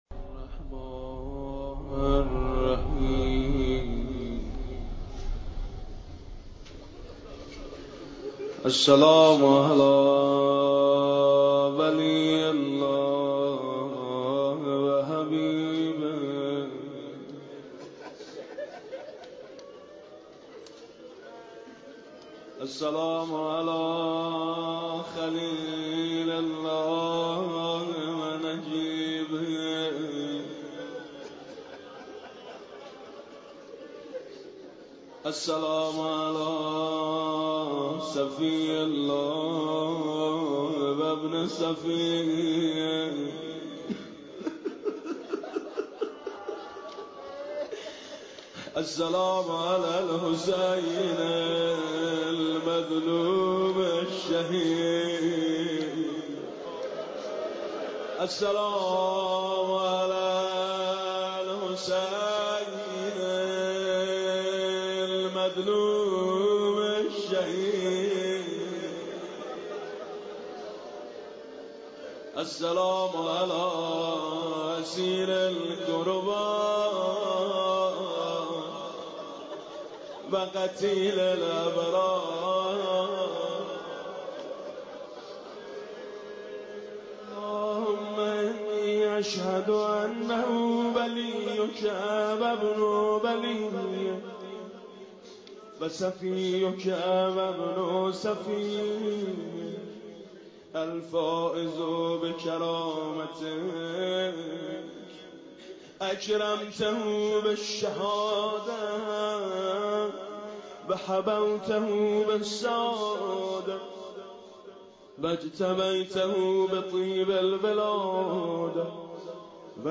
القارئ : ميثم مطيعي